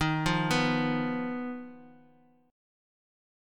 D#sus2#5 chord